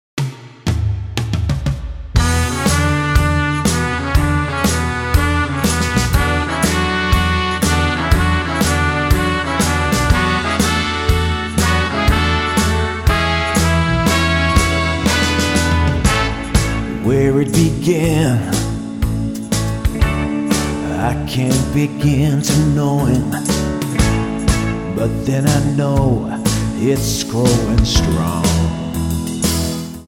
Tonart:A-Bb inc. encore Multifile (kein Sofortdownload.
Die besten Playbacks Instrumentals und Karaoke Versionen .